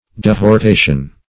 Search Result for " dehortation" : The Collaborative International Dictionary of English v.0.48: Dehortation \De`hor*ta"tion\, n. [L. dehortatio.]